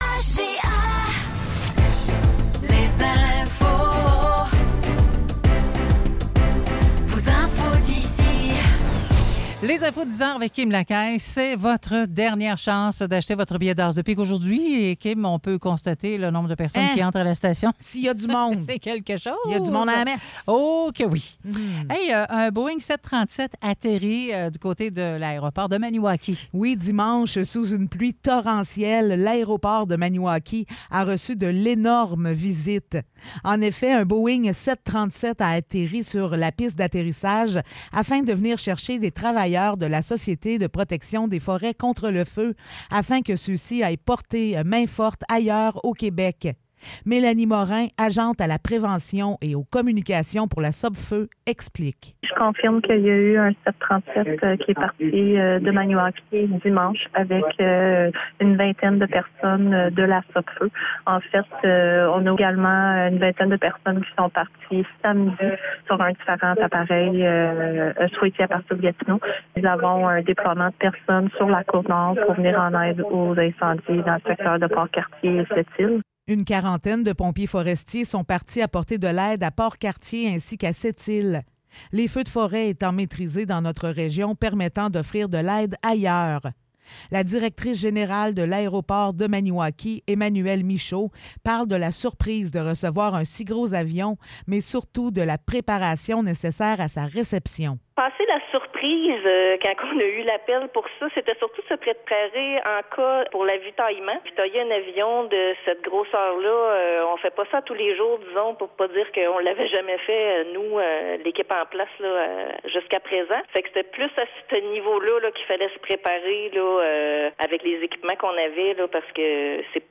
Nouvelles locales - 26 juin 2024 - 10 h